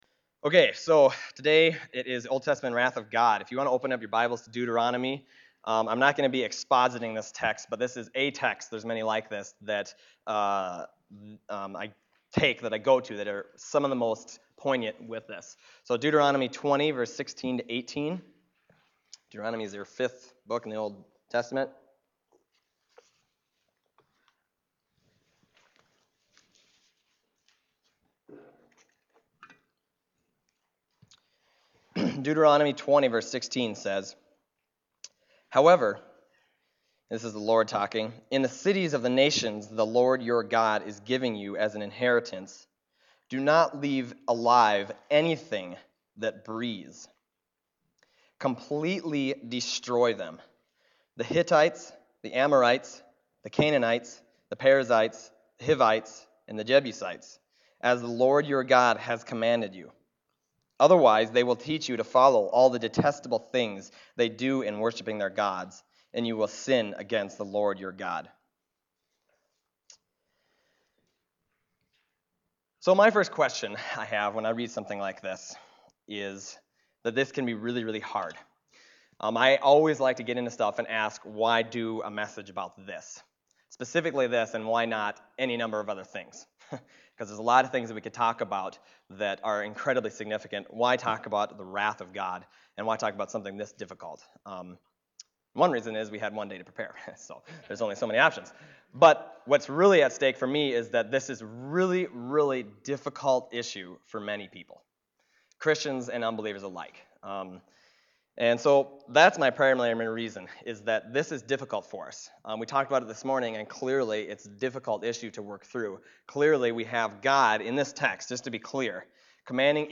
Other Passage: Deuteronomy 20:16-18 Service Type: Sunday Morning Deuteronomy 20:16-18 « Let Not Your Hearts Be Troubled Power and Prayer